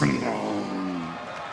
Play, download and share Taker Gurgle original sound button!!!!
taker-gurgle.mp3